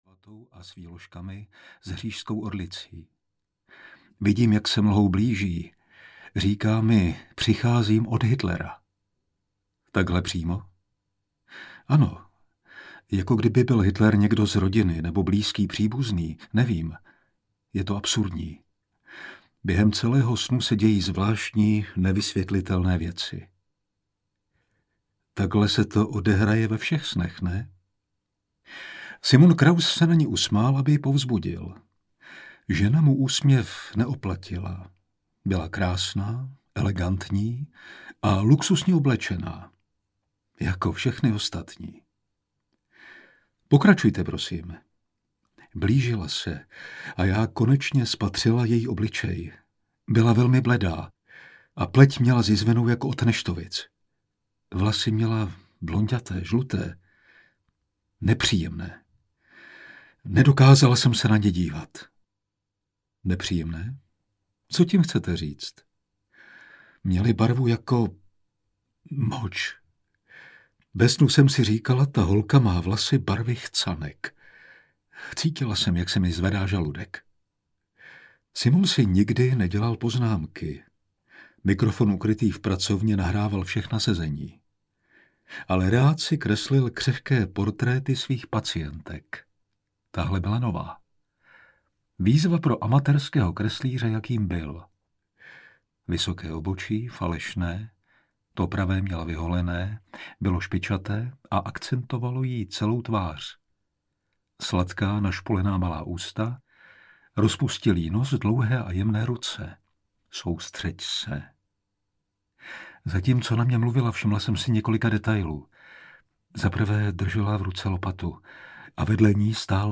Snoubenky smrti audiokniha
Ukázka z knihy
• InterpretFrantišek Kreuzmann